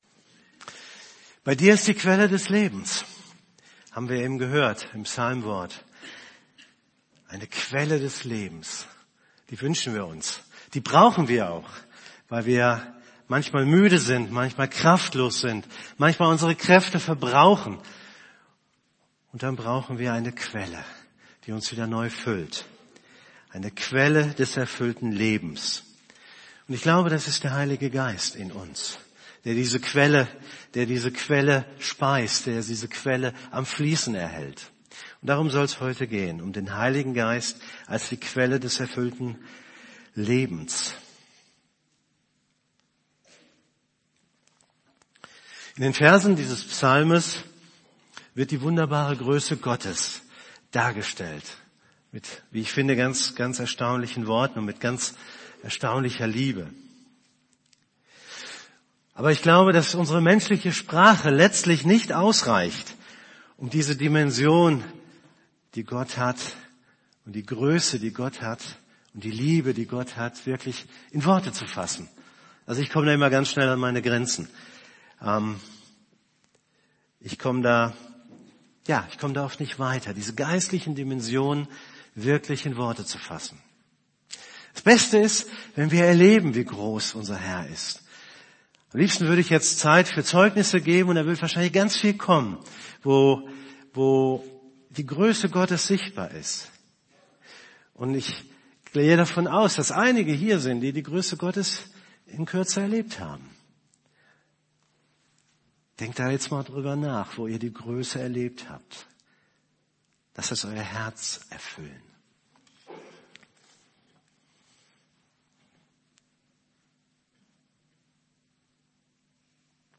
> Übersicht Predigten Der Heilige Geist als Quelle eines erfüllten Lebens Predigt vom 07.